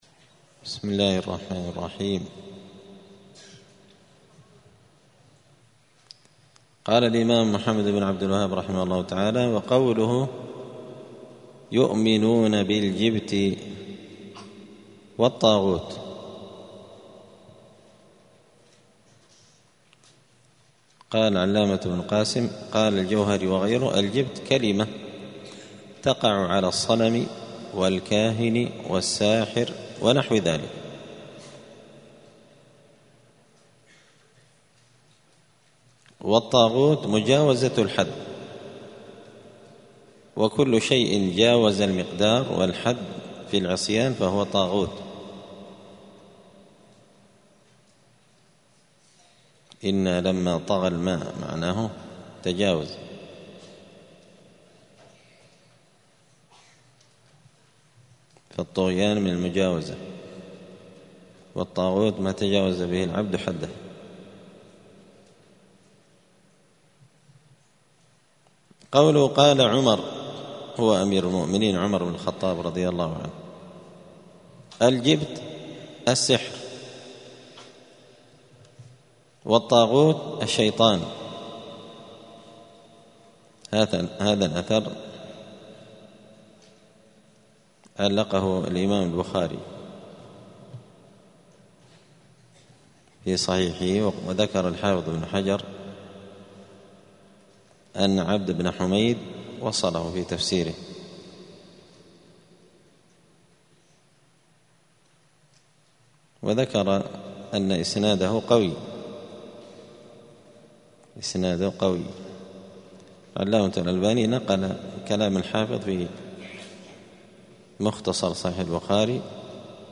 دار الحديث السلفية بمسجد الفرقان قشن المهرة اليمن
*الدرس الثامن والستون (68) {باب ماجاء في السحر}*